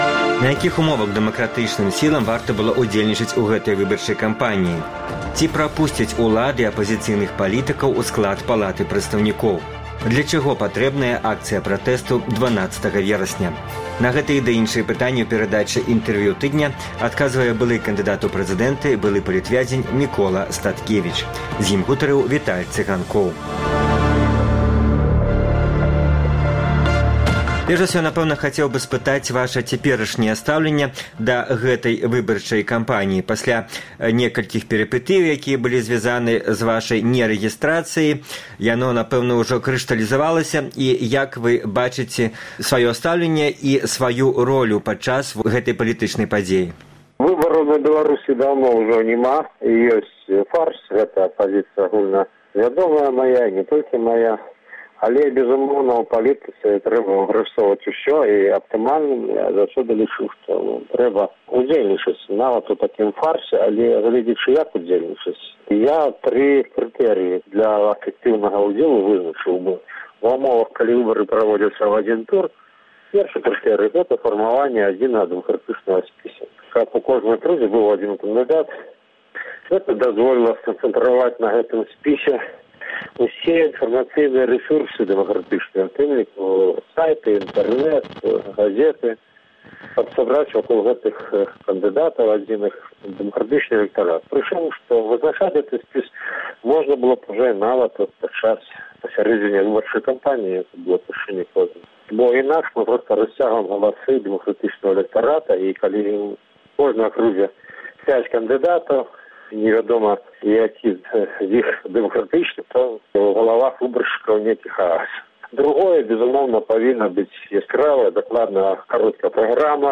Для чаго патрэбная акцыя пратэсту 12 верасьня? На гэтыя ды іншыя пытаньні ў перадачы “Інтэрвію тыдня” адказвае былы кандыдат у прэзыдэнты, былы палітвязень Мікола Статкевіч.